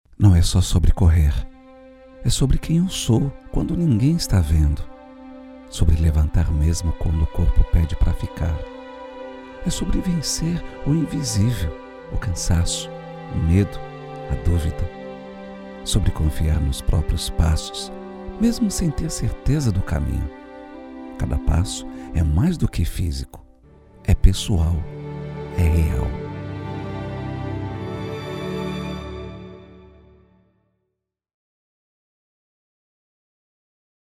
VOZ SUSSURRADA NATURAL: